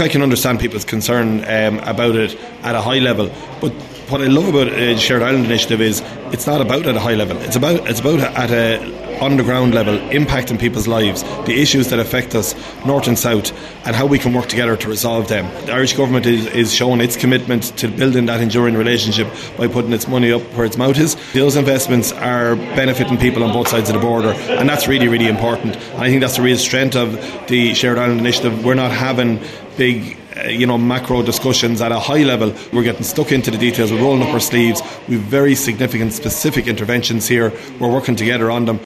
The Kildare South TD says the investments being made are helping people on both sides of the border: